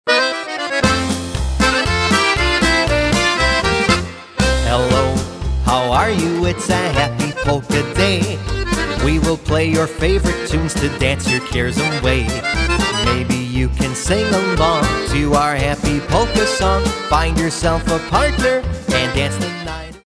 A Milwaukee, Wisconsin Polka Band